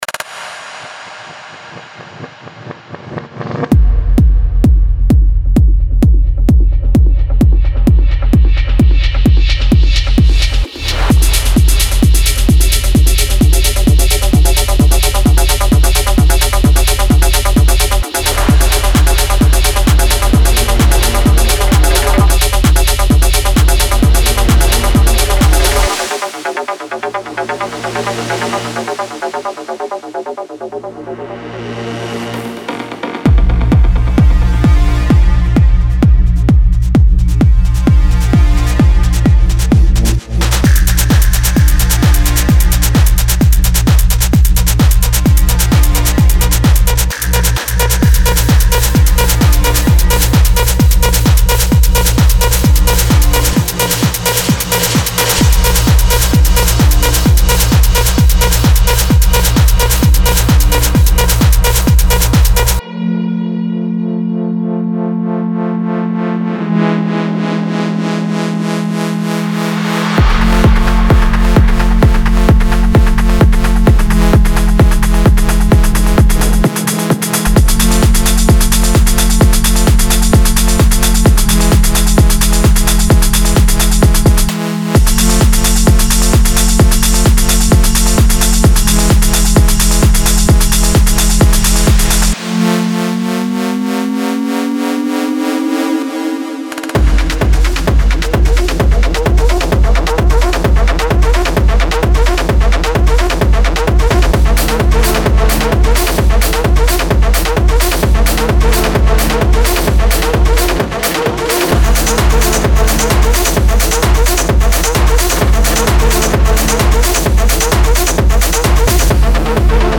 driving basses and drums for techno music production
bad-ass techno sounds